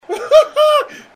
haha1.mp3